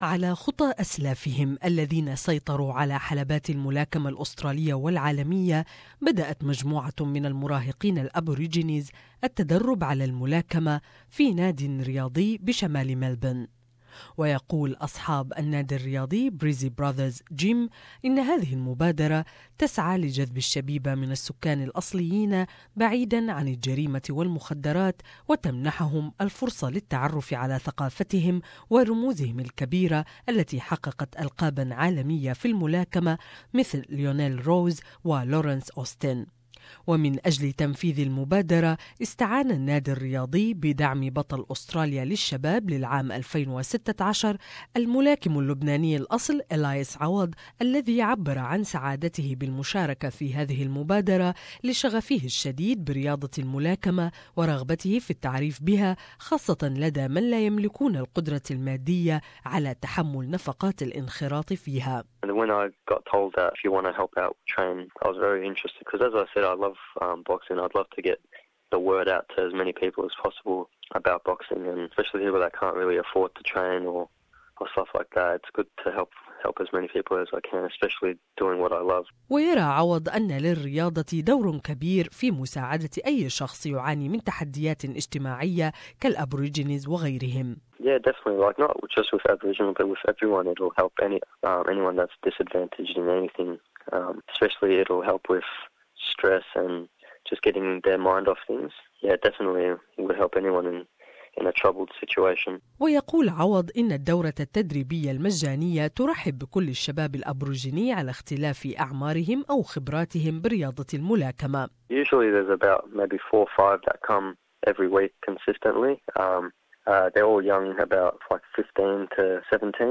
More in this intreview